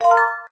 chime_1.ogg